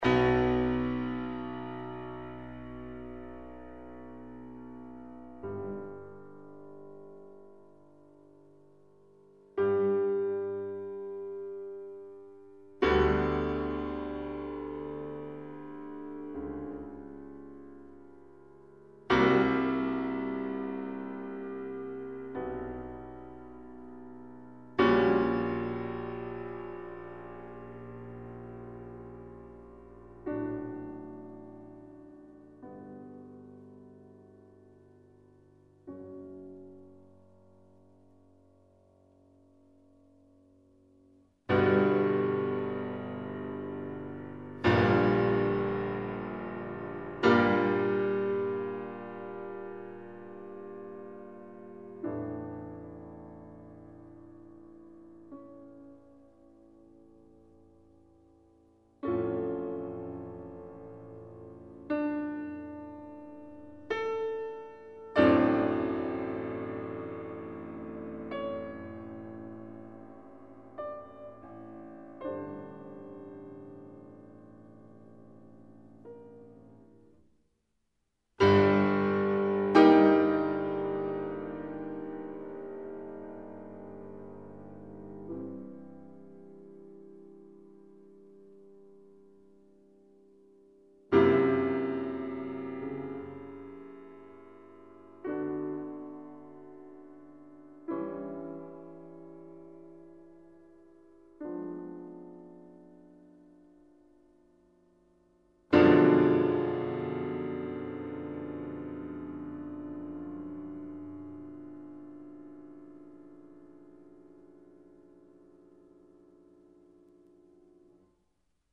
1. Fantasmi di un remoto passato: devozioni, speranze, martirio ... Lunghi accordi, lenti, da cima a fondo
Catacombe (versione pianistica)